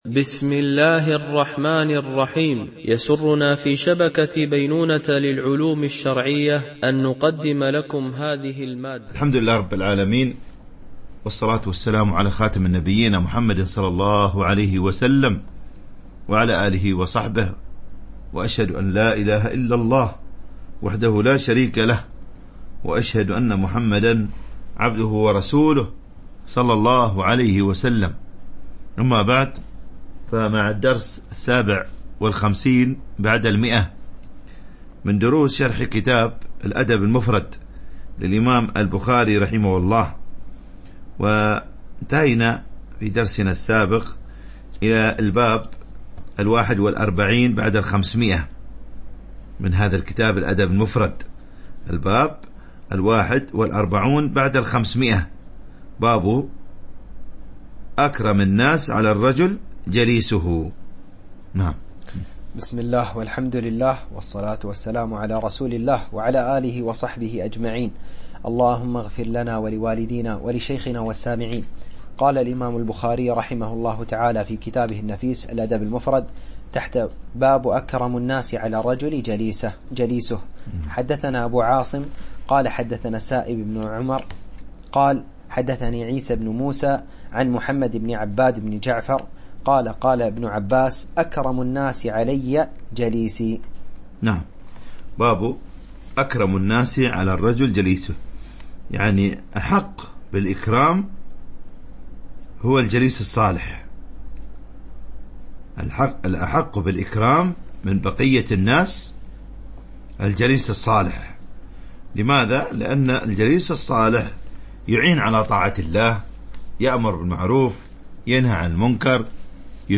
شرح الأدب المفرد للبخاري ـ الدرس 157 ( الحديث 1145 - 1152 )